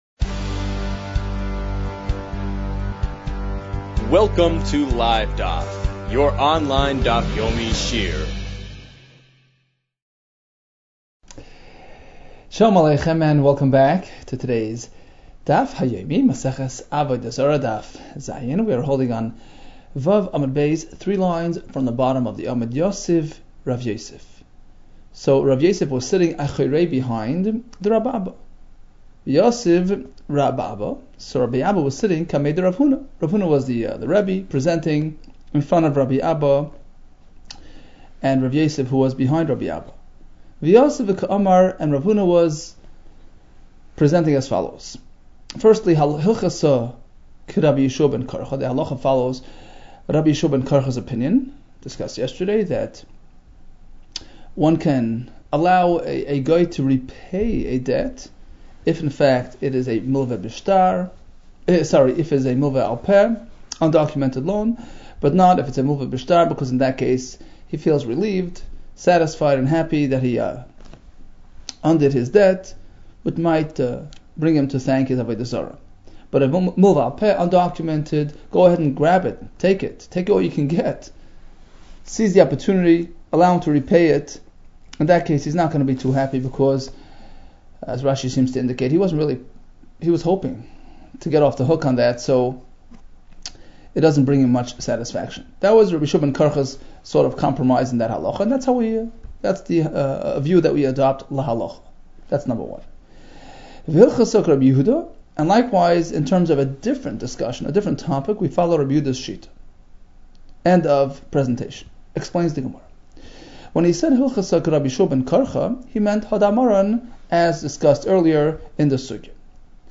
Avodah Zarah 7 - עבודה זרה ז | Daf Yomi Online Shiur | Livedaf